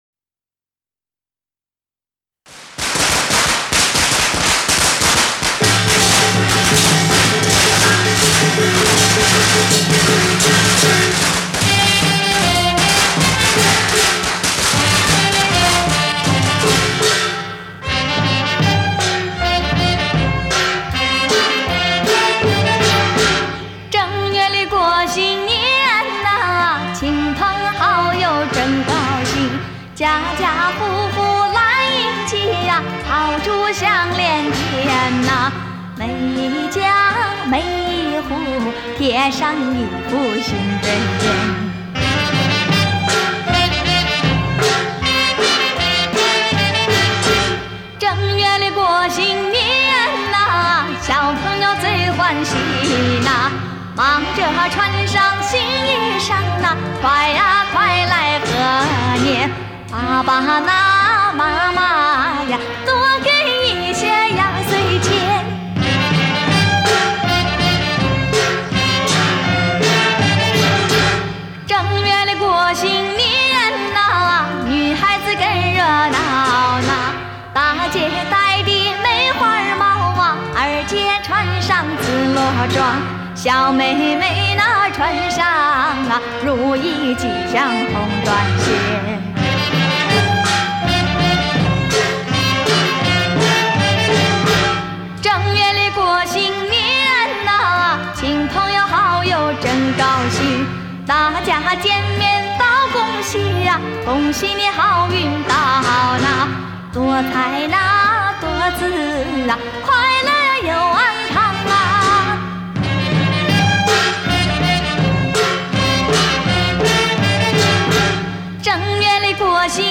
这是一张黑胶唱片的复刻版本，一张很好的新年音乐.